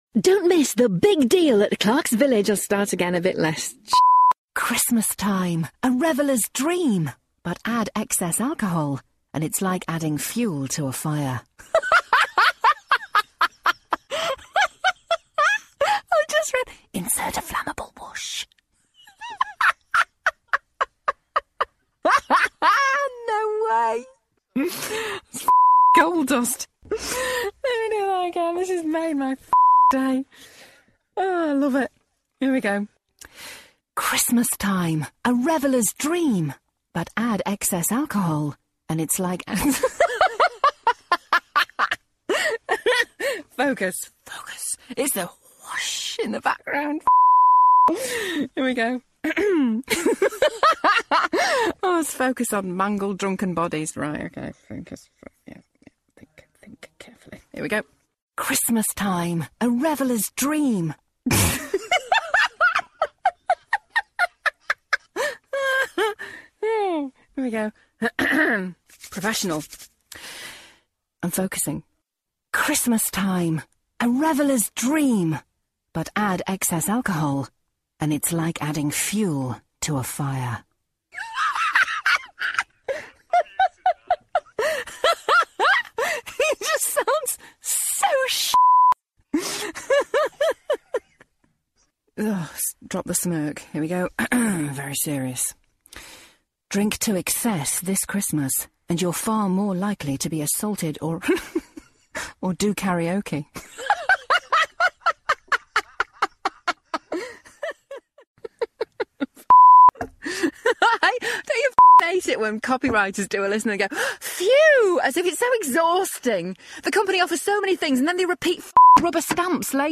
Christmas Voiceover Outtakes